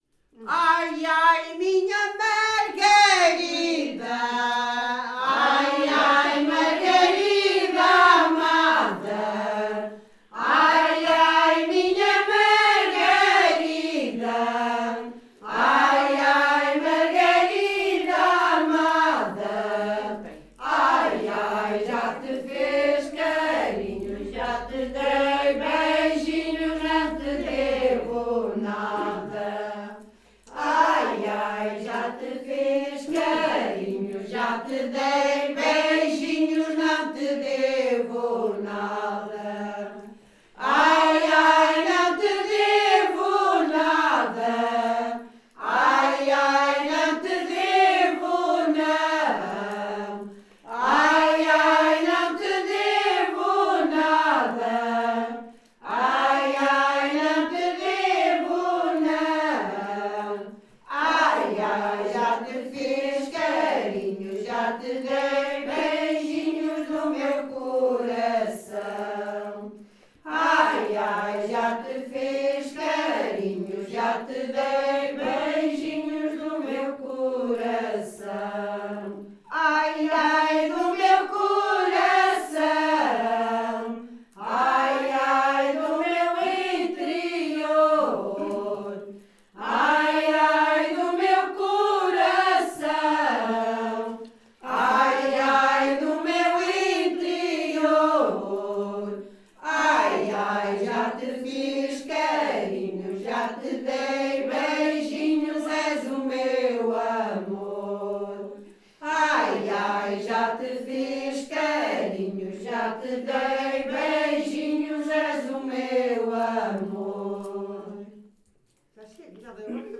Grupo Etnográfico de Trajes e Cantares do Linho de Várzea de Calde - Ensaio - Ai ai minha Margarida. Calde a 5 Maio 2016.